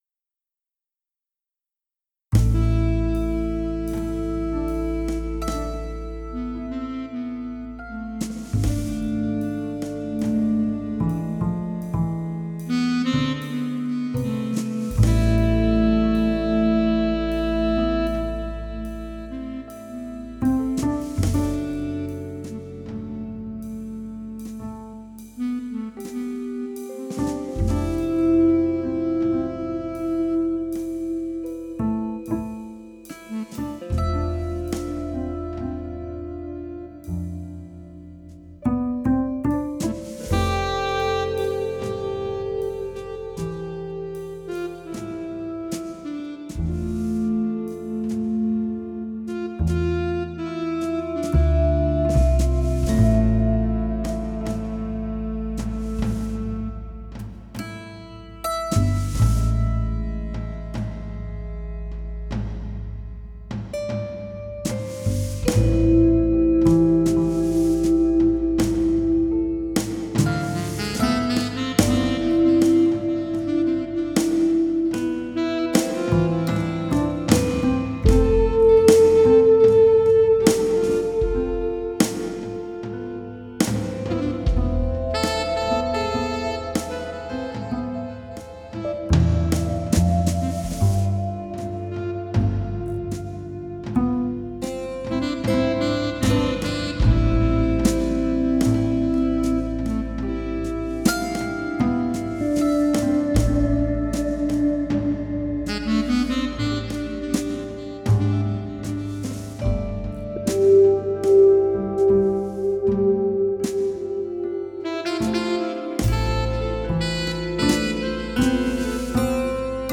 Du très bon jazz electronic qui apporte plaisir et calme.